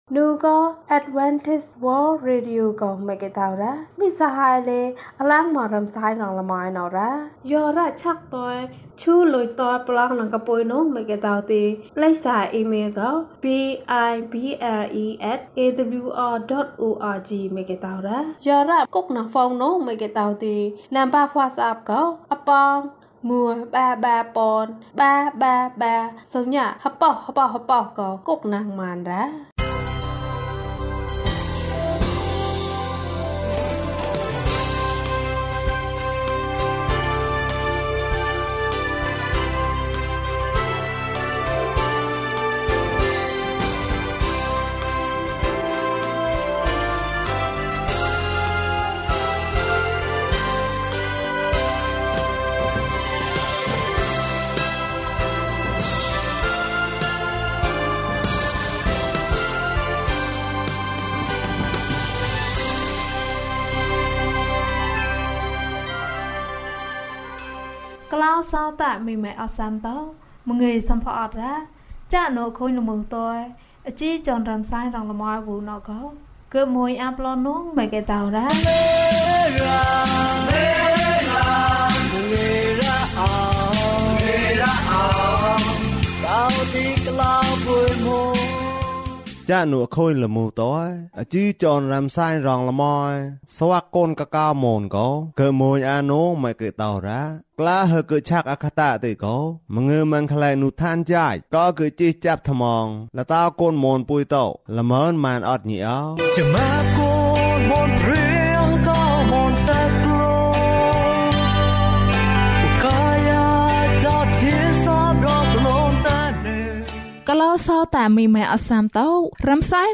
ခရစ်တော်ထံသို့ ခြေလှမ်း။၅၈ ကျန်းမာခြင်းအကြောင်းအရာ။ ဓမ္မသီချင်း။ တရားဒေသနာ။